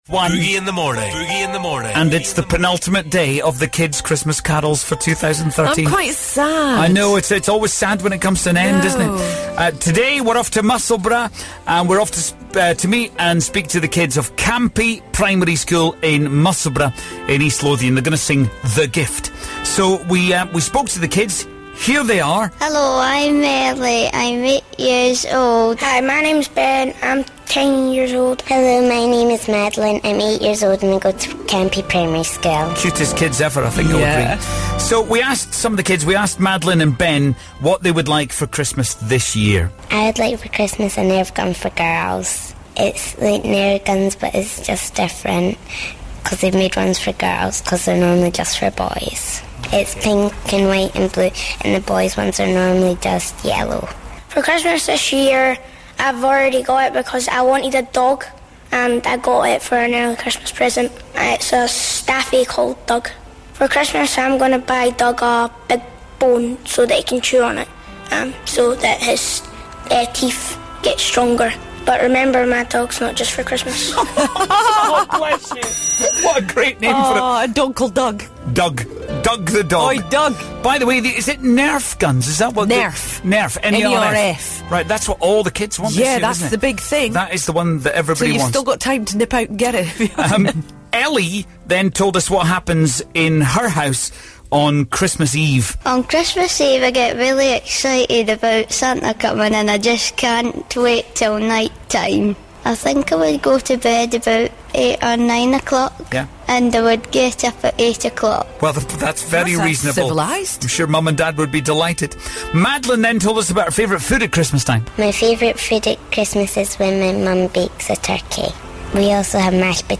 Christmas Carols - Campie Primary School
The kids from Campie Primary School in Musselburgh sing 'The Gift'.